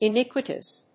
発音記号
• / ɪníkwəṭəs(米国英語)
iniquitous.mp3